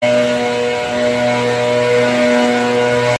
Este es el insoportable ruido que sufren los vecinos del centro de Almendralejo
En el corazón de la ciudad, específicamente en el parque de la Libertad y la avenida de la Paz, los vecinos están enfadados por un ruido molesto proveniente del soplador utilizado por la empresa de limpieza, justo en las primeras horas del día.